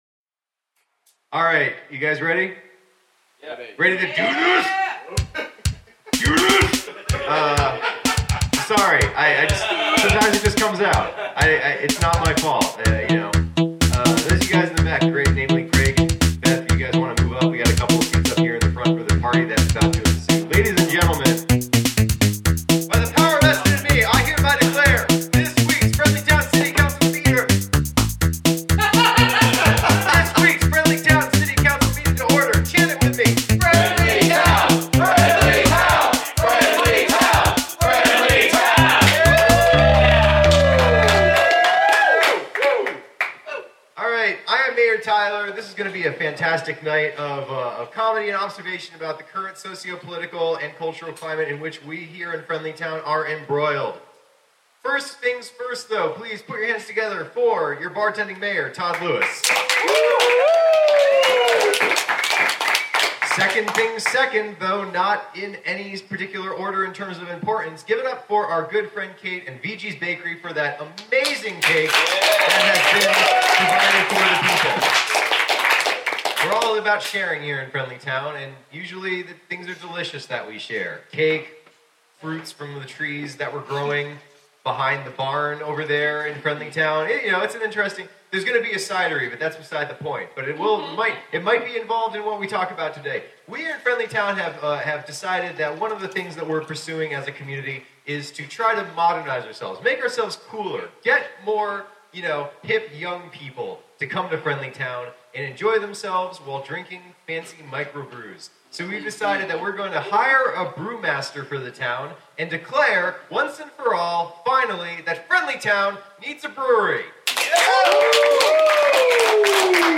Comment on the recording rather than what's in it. Recorded Live at the Pilot Light October 2, 2017, Knoxville TN